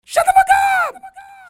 SouthSide Chant (10).wav